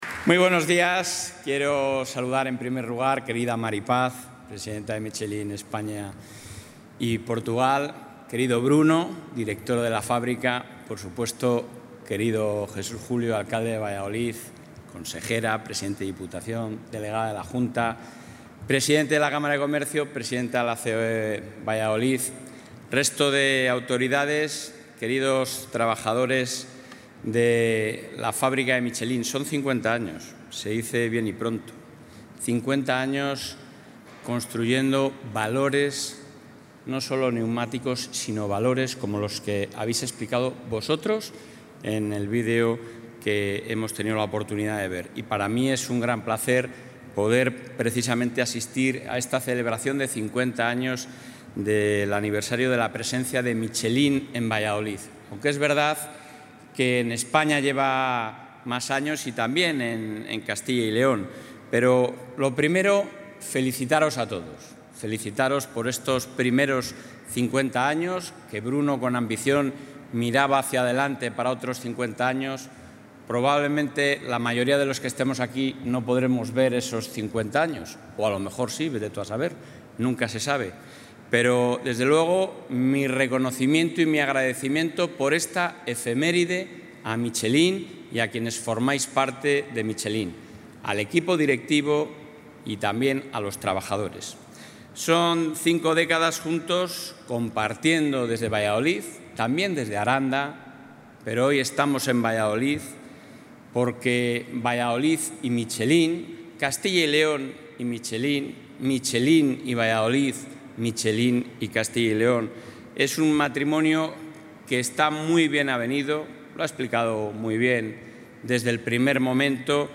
Intervención del presidente de la Junta.
El presidente de la Junta de Castilla y León, Alfonso Fernández Mañueco, ha participado en el acto de celebración del 50 aniversario de la planta de Michelin en Valladolid, donde ha felicitado a la compañía y ha agradecido su compromiso con la Comunidad.